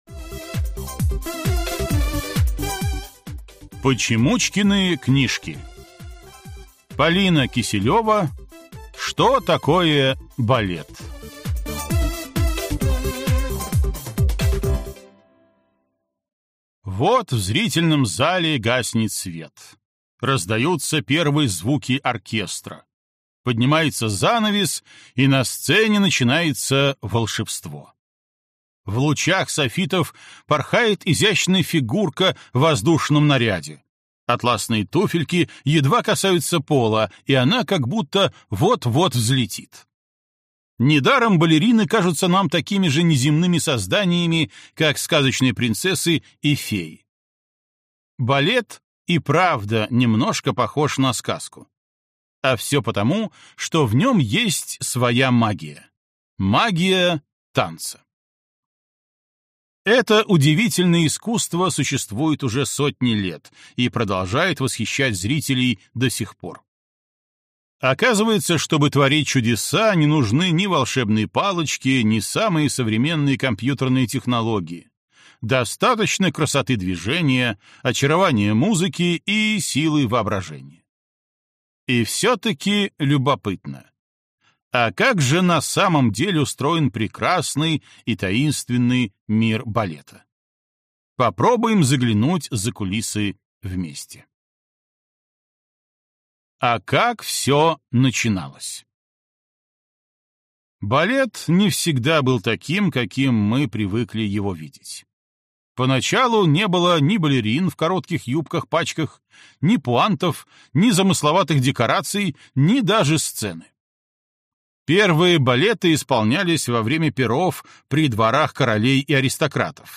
Аудиокнига Что такое балет?